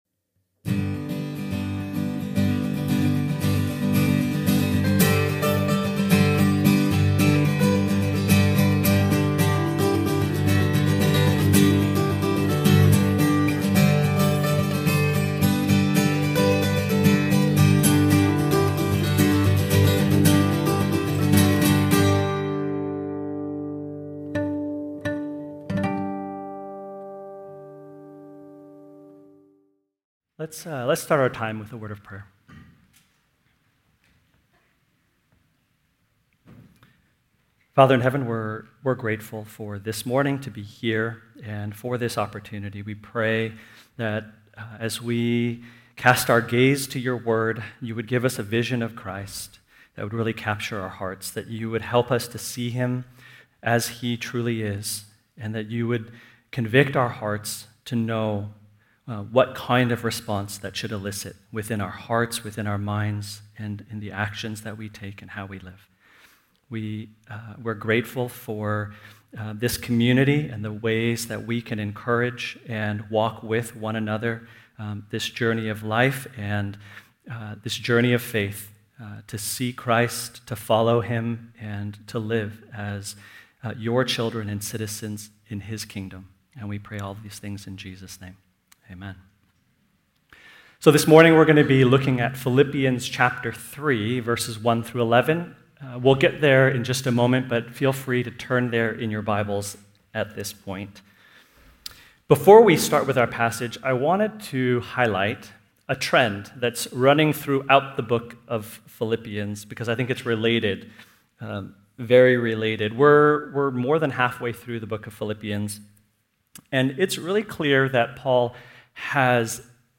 Sermon Podcast from Community Christian Fellowship in Edmonds, WA.